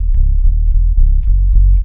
BASS 3 129-R.wav